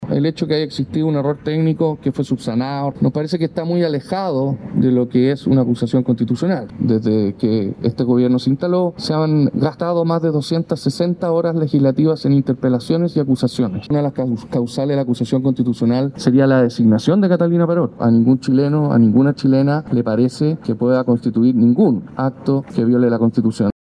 El propio ministro de la Segpres, Juan José Ossa, pidió que no se gasten más horas legislativas en esto, ya que hasta ahora se ha ocupado un gran tiempo en interpelaciones y acusaciones en contra de autoridades.